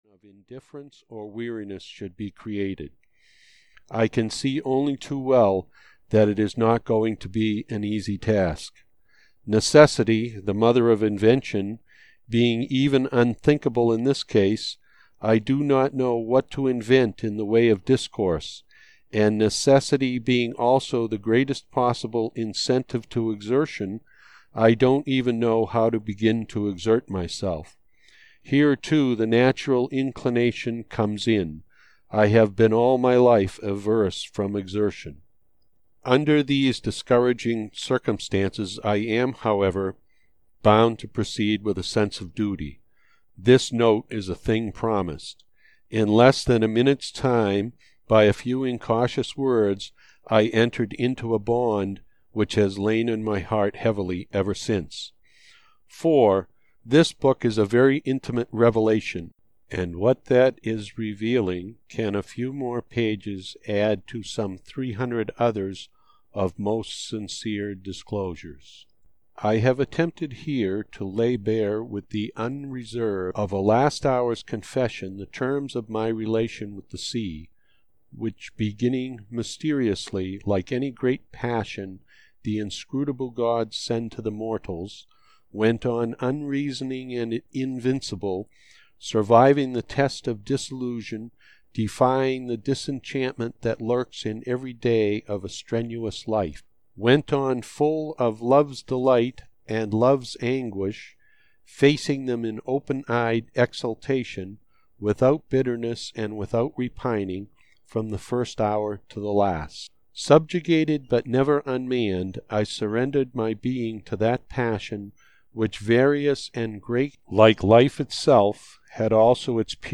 The Mirror of the Sea (EN) audiokniha
Ukázka z knihy